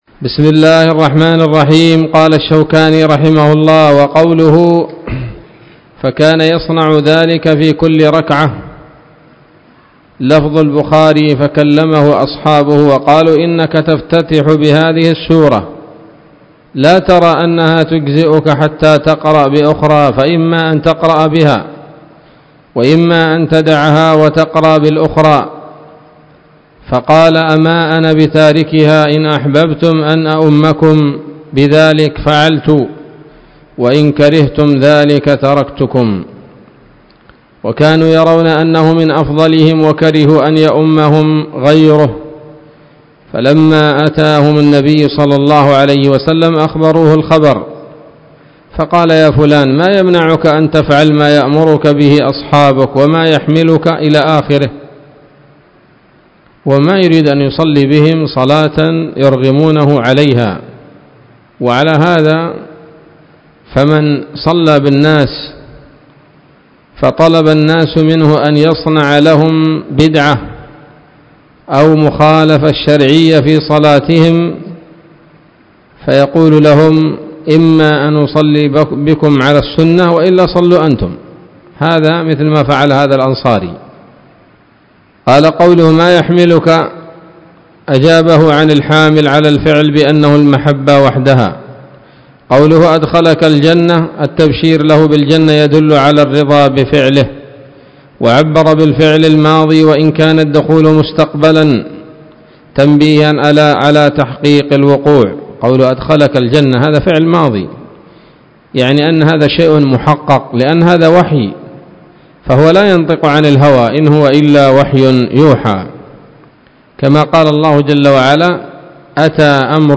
الدرس الرابع والأربعون من أبواب صفة الصلاة من نيل الأوطار